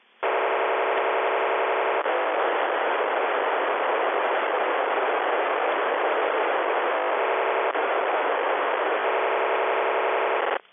无线电声音 " 令人毛骨悚然的无线电声音干扰
描述：来自收音机的令人毛骨悚然的声音效果免费使用。
标签： 收音机 静态 爬行 干扰 恐怖 噪音
声道立体声